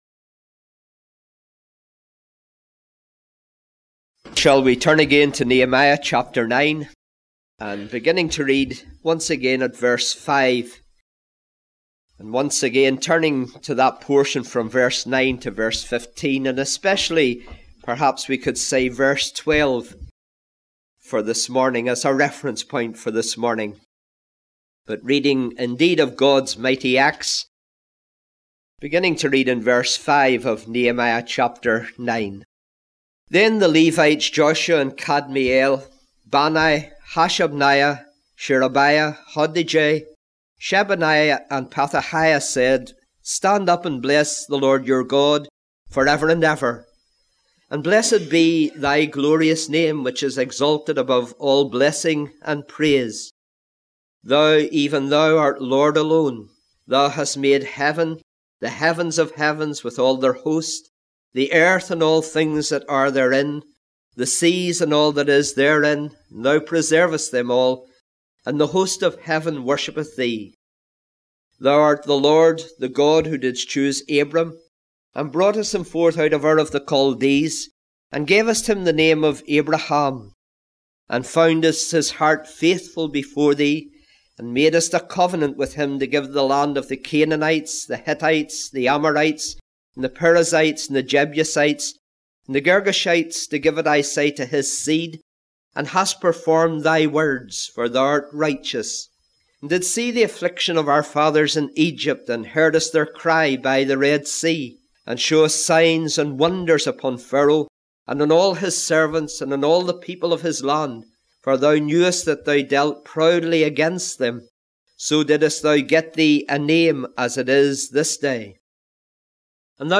Sermons from the Book of Nehemiah. The Gospel is expounded and applied.